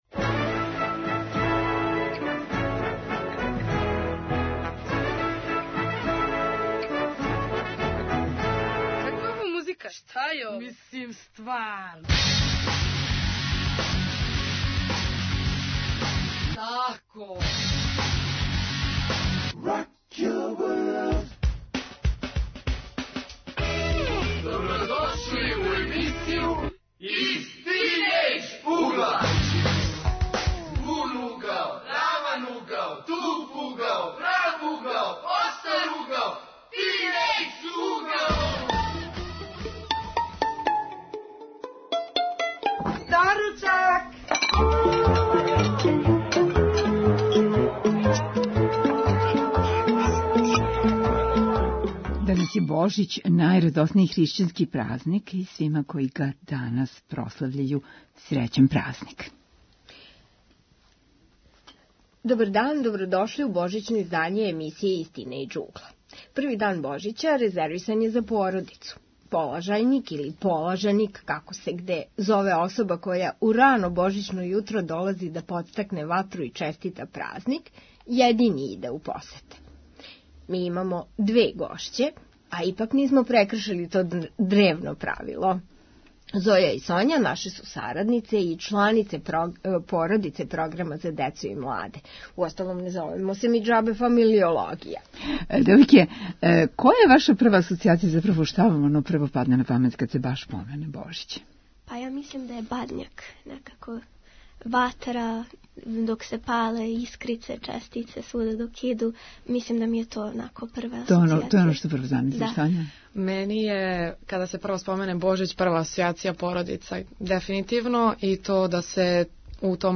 Иако се на Божић не иде у госте, у емисији Из тинејџ угла, окупиће се млади сарадници и пријатељи програма да проћаскају о породичним празницима.